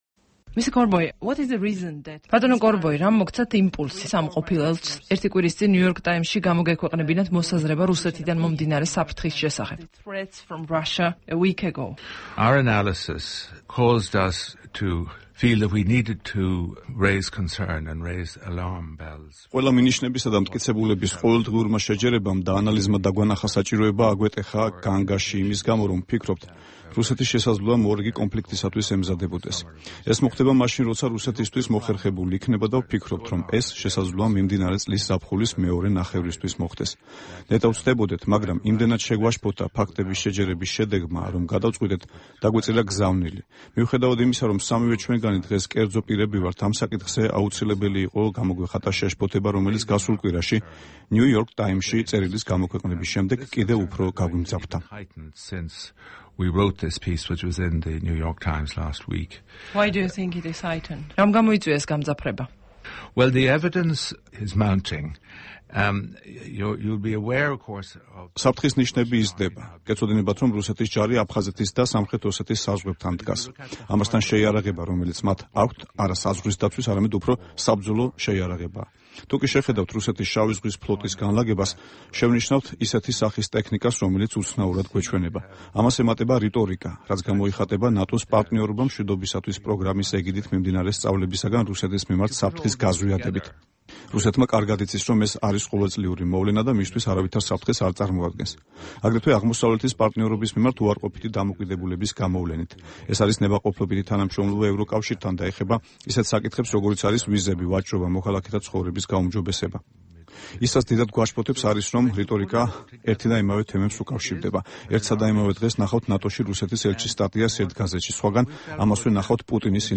ინტერვიუ საქართველოში ევროკომისიის წარმომადგენლობის ყოფილ ხელმძღვანელთან